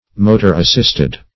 motor-assisted - definition of motor-assisted - synonyms, pronunciation, spelling from Free Dictionary